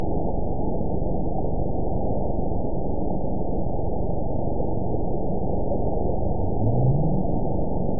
event 922864 date 04/29/25 time 02:55:29 GMT (1 month, 2 weeks ago) score 9.48 location TSS-AB02 detected by nrw target species NRW annotations +NRW Spectrogram: Frequency (kHz) vs. Time (s) audio not available .wav